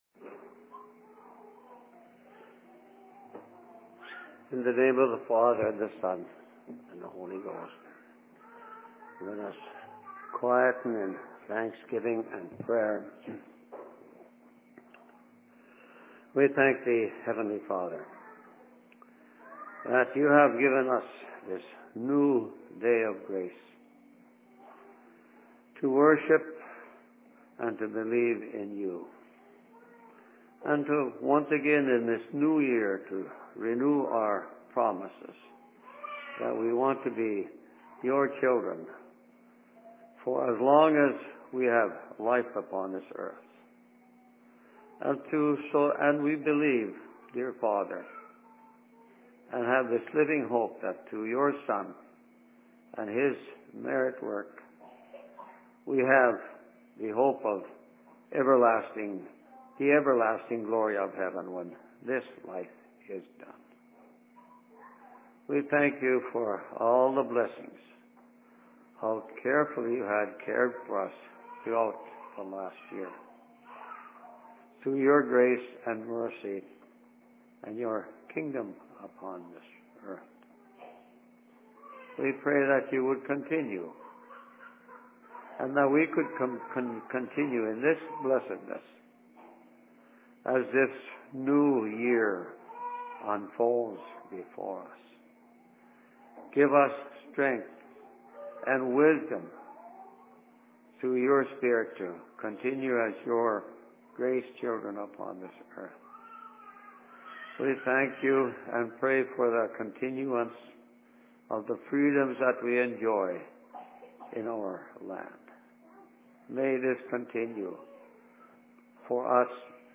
Sermon in Outlook 01.01.2012
Location: LLC Outlook